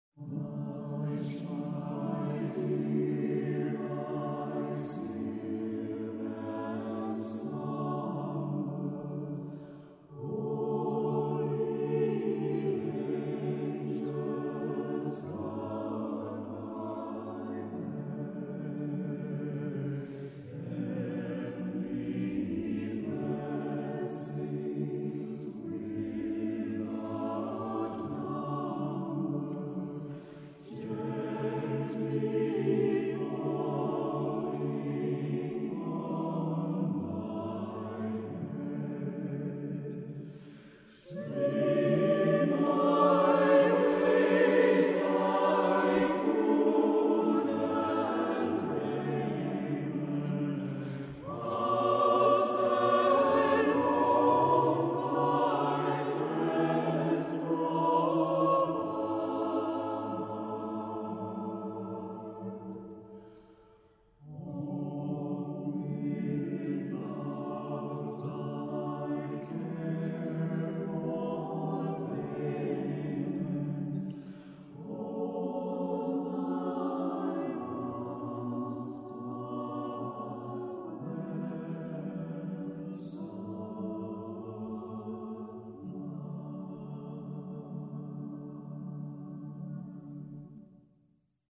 An important part of The Masterwork Chorus is a chamber ensemble made up of auditioned members of the full chorus. Called The Masterwork Camerata, it is a mixed ensemble of 15-20 voices.
Hodie Christus Natus Est - Jan Pieterszoon Sweelinck (1562-1621) - As Performed by The Masterwork Camerata Watts Cradle Hymn - Isaac Watts (1674-1748) - As Performed by The Masterwork Camerata hodie_retakes-05 watts_cradle_retakes-03